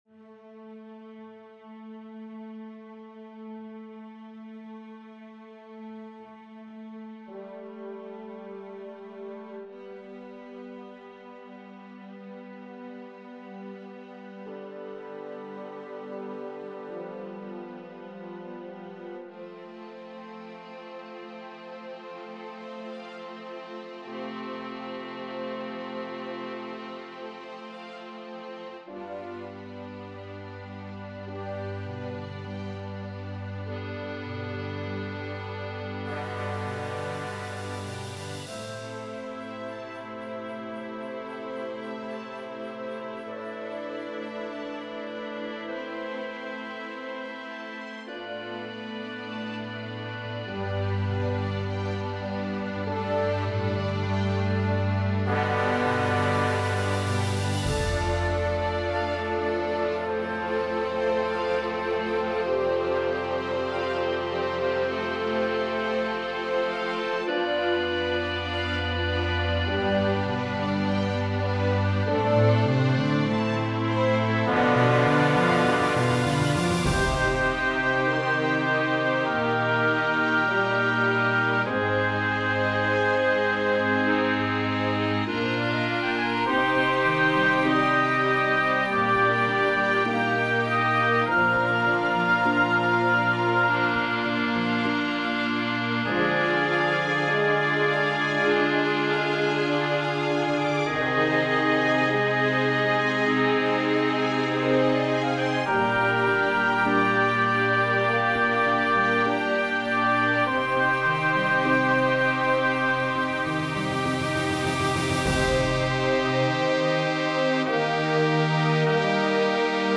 The Great Lighthouse - Part I - Orchestral and Large Ensemble - Young Composers Music Forum
Part one is written in largo - A minor and is called Discovery.